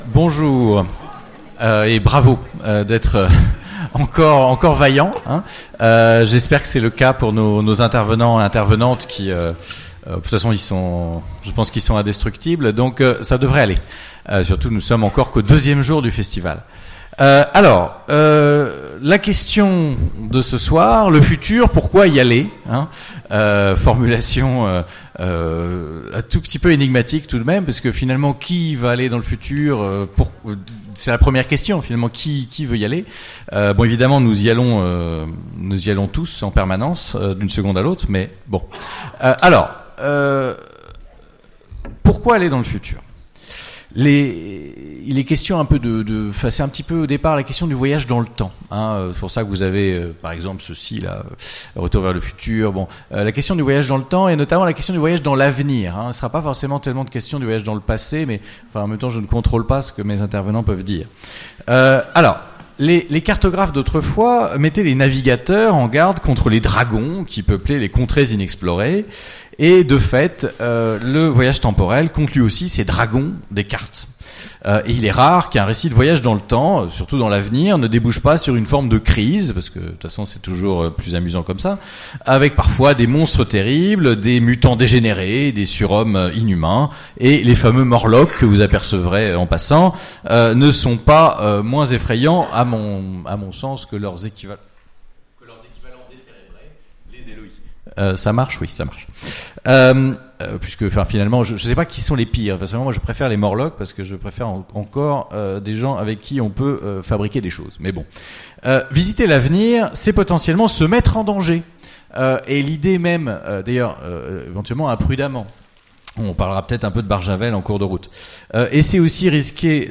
Utopiales 2017 : Conférence Le futur, pourquoi y aller ?